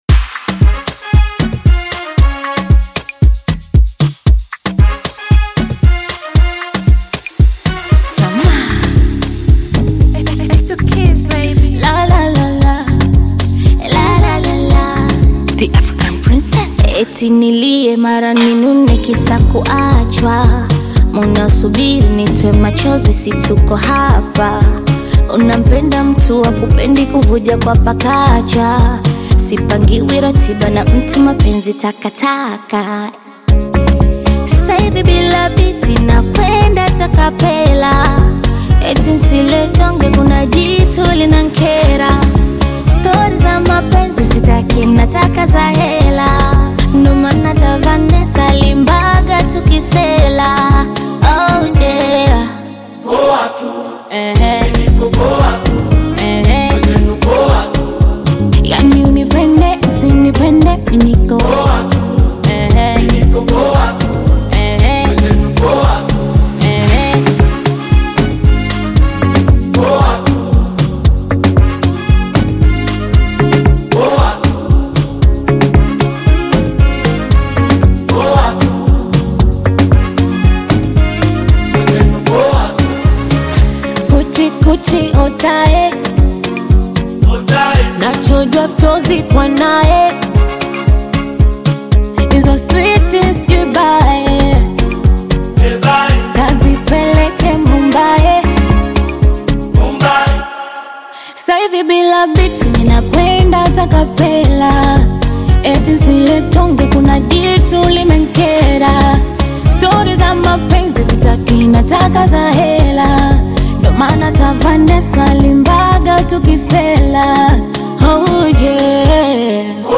infectiously catchy banger